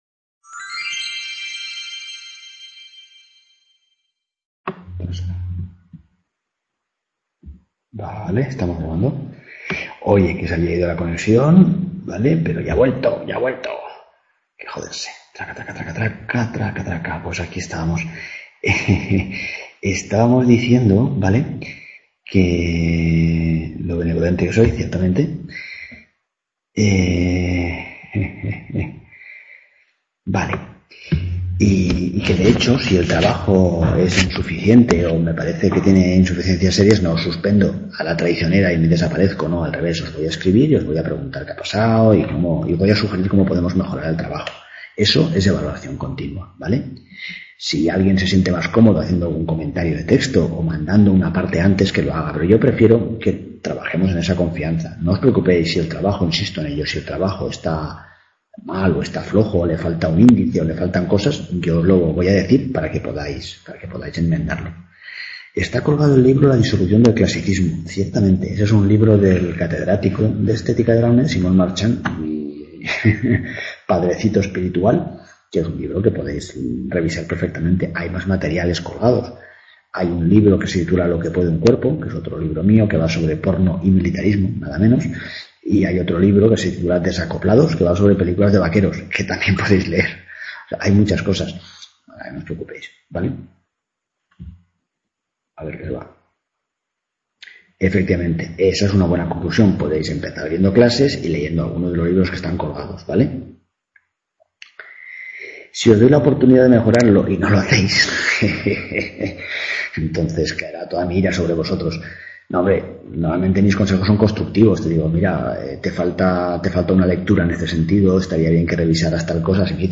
Clase de bienvenida, segunda parte
clase de bienvenida, segunda parte, porque la grabacion se cortó y hubo que hacer un cachito mas de clase, mas se perdio en cuba y -al parecer- salieron cantando!
Video Clase